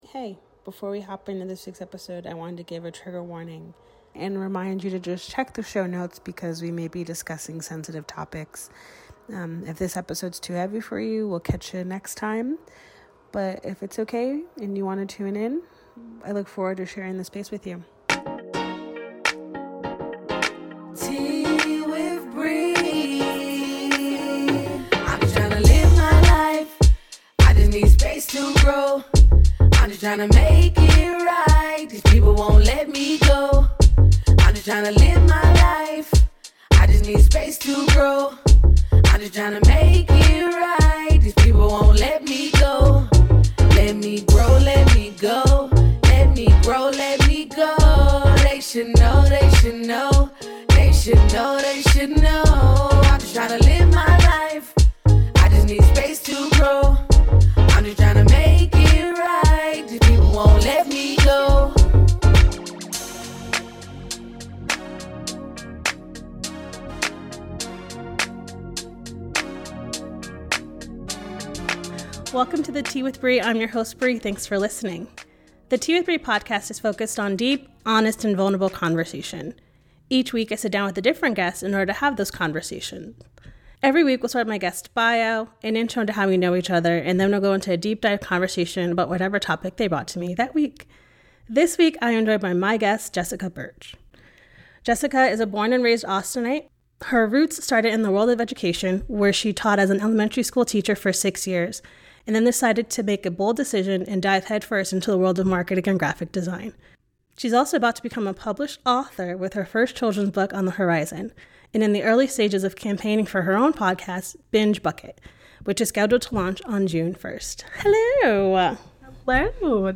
----- This podcast is recorded via Riverside FM.